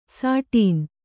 ♪ sāṭīn